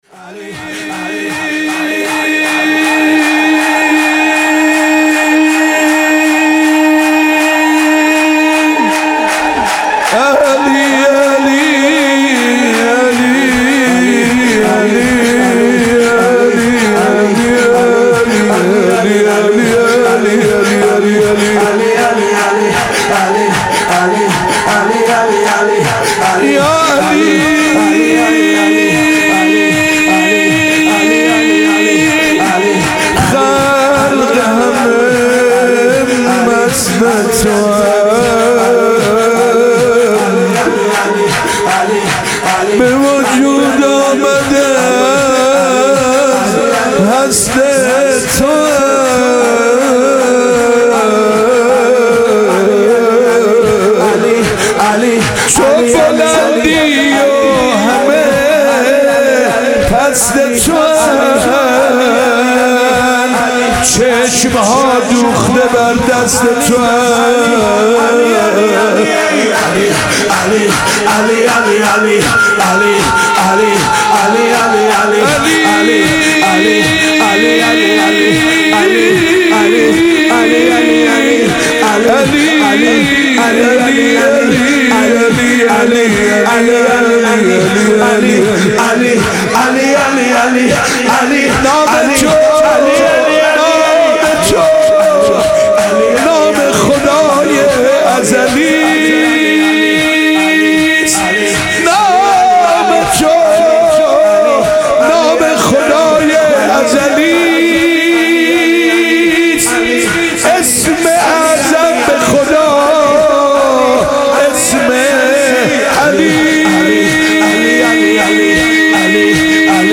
سرود میلاد پیامبر و امام صادق (علیهم السلام)